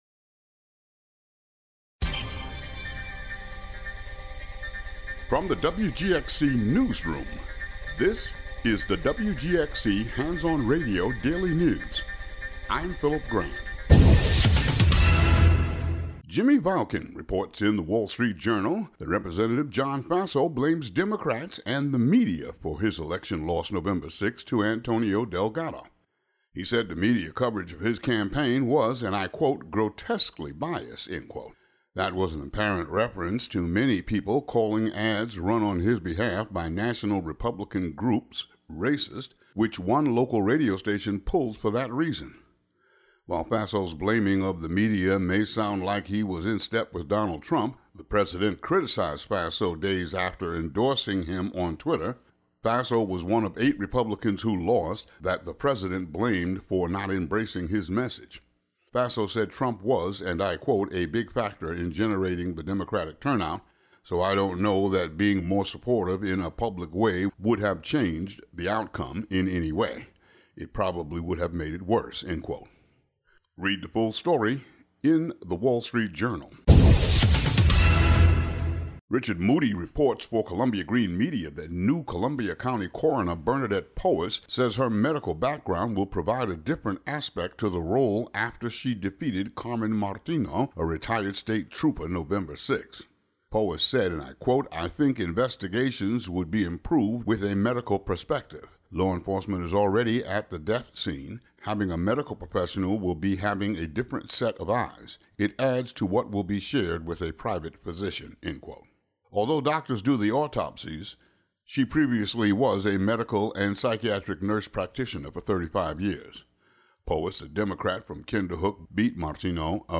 Today's local news.